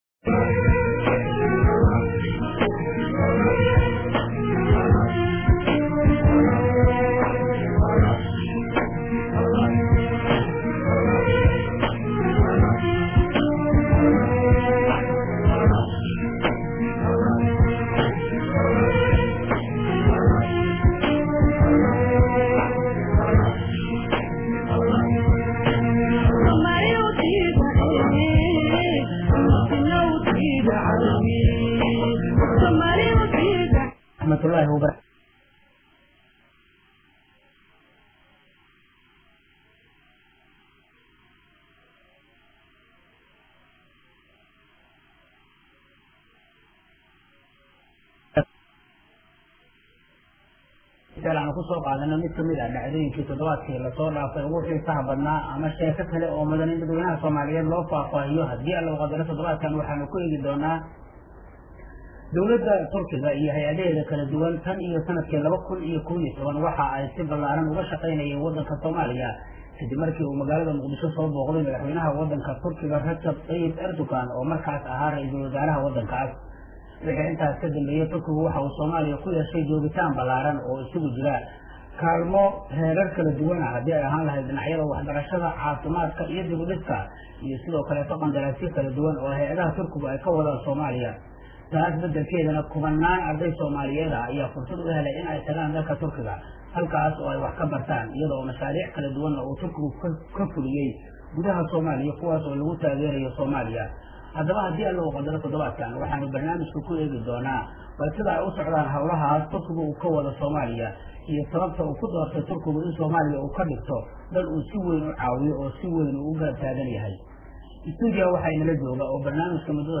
Barnaamijka kulanka todobaadka ee ka baxa Radio Muqdisho iyo Telefishinka Qaranka ayaa waxaa marti ku ahaa oo ka qeybgalay danjiraha dowlada Turkiga u fadhiya Soomaaliya Olgen Bekar oo si faah faahsan uga hadlay cilaaqaadka u dhaxeeya Soomaaliya iyo Turkiga iyo mashaariicda horumarineed ee Turkigu ka fulinayaan dalkeena.
Danjiraha ayaa su’aalo uga jawaabay qaar ka mid ah dhageystayaasha barnaamijka oo ku kala sugan dalka iyo dibadda.